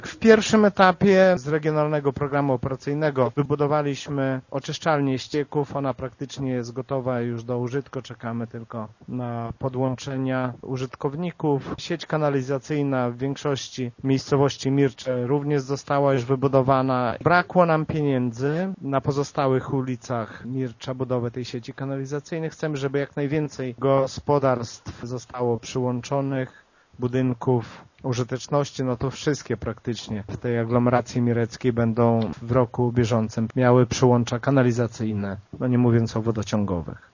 To kolejny etap inwestycji, którą rozpoczęliśmy w 2011 roku – przypomina wójt Lech Szopiński: